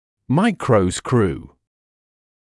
[‘maɪkrəuskruː][‘майкроускруː]микровинт (также micro-screw)